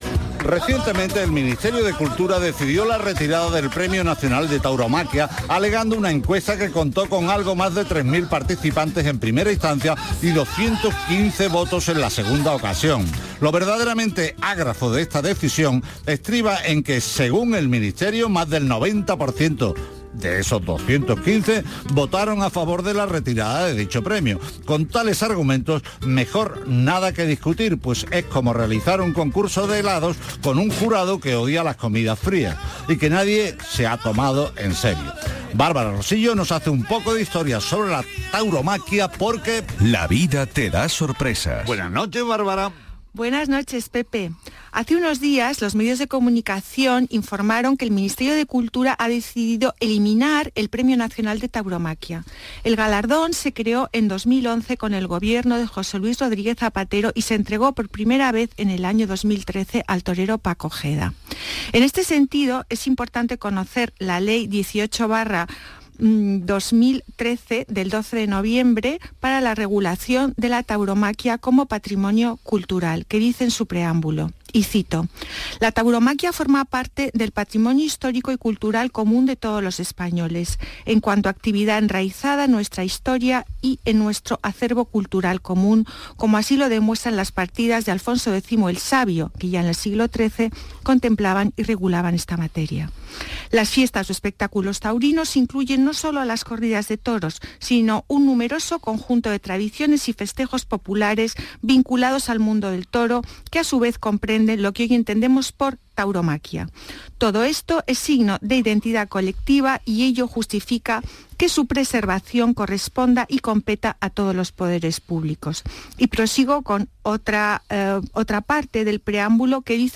En este enlace podréis escuchar mi sección «La vida te da sorpresas» en el programa de Radio Andalucía Información, «Patrimonio andaluz» del día 15/09/2024.
toros-programa-radio.mp3